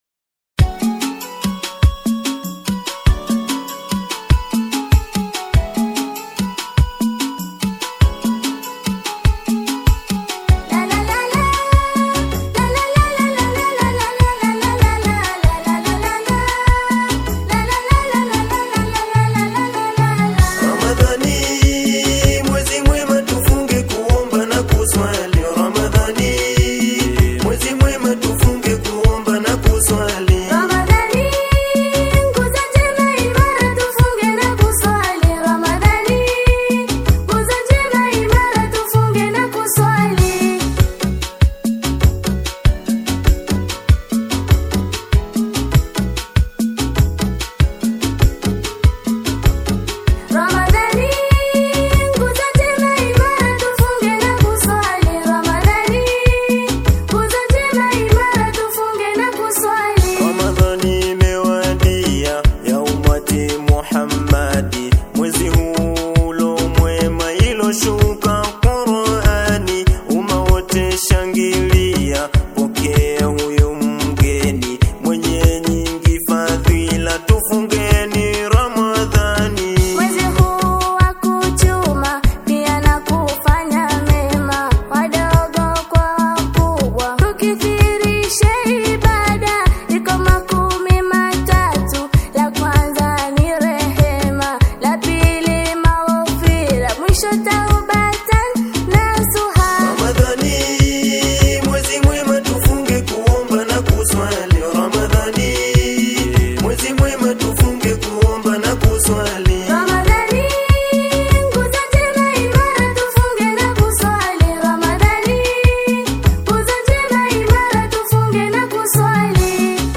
Qaswida